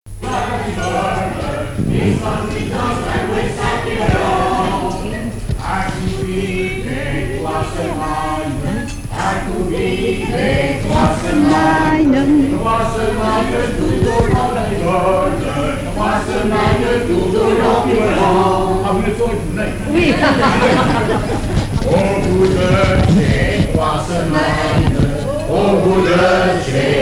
Genre laisse
Veillée à Champagné
Catégorie Pièce musicale inédite